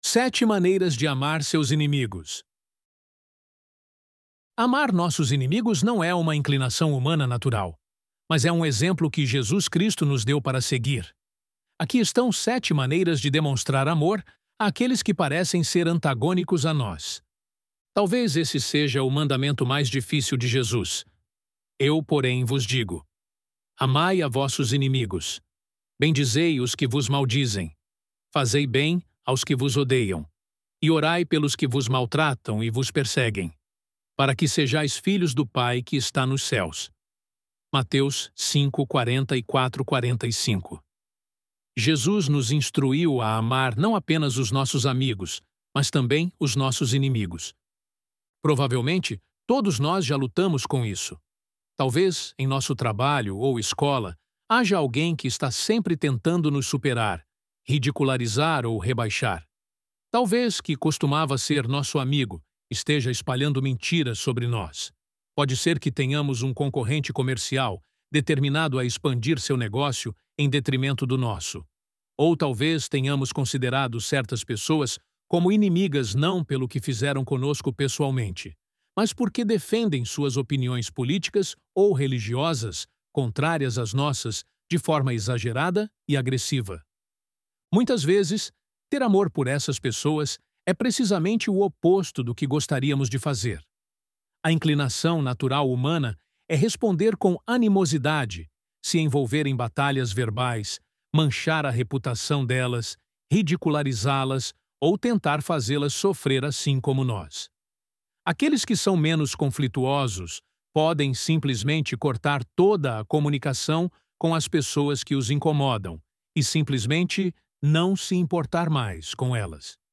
ElevenLabs_Sete_Maneiras_de_Amar_Seus_Inimigos.mp3